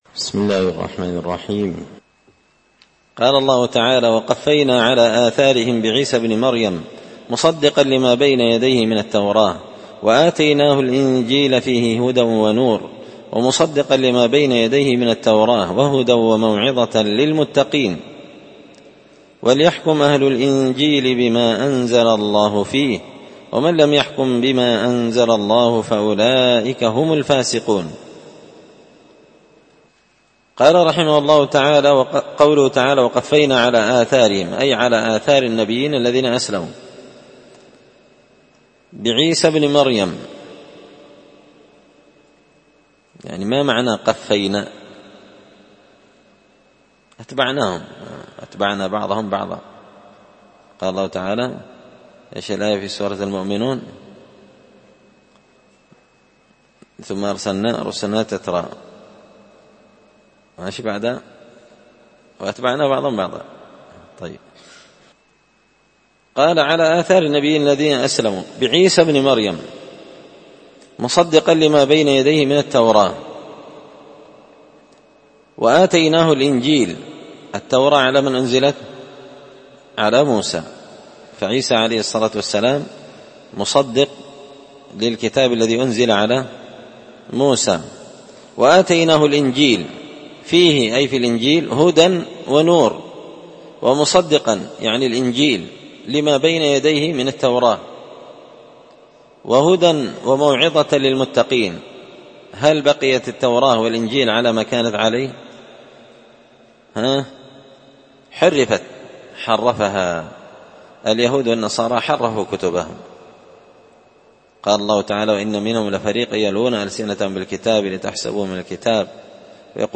📢 ألقيت هذه الدروس في 📓 # دار _الحديث_ السلفية _بقشن_ بالمهرة_ اليمن 🔴مسجد الفرقان